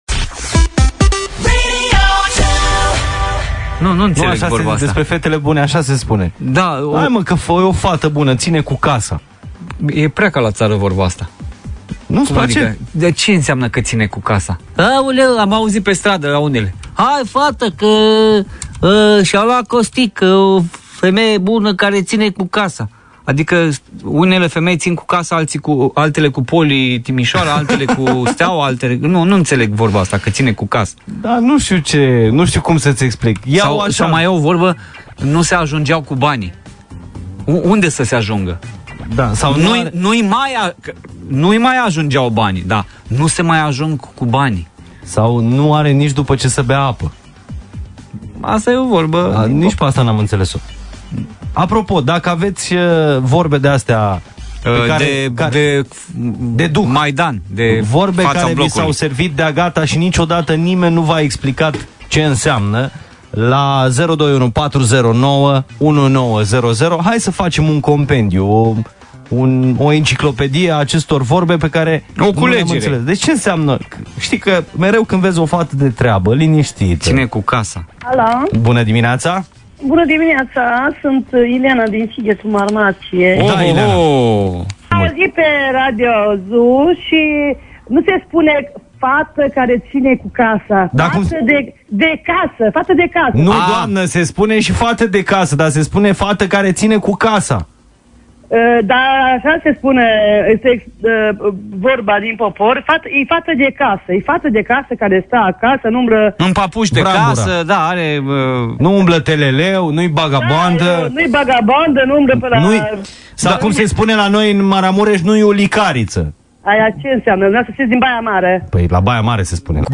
Mihai si Daniel se tot lovesc de expresii romanesti, mai vechi sau mai noi, care sunt de-a dreptu´ bizare. Pentru explicarea lor, au apelat la ascultatori.
Download Etichete: expresii ascultatori telefoane Pe bune, ţi-a plăcut sau nu?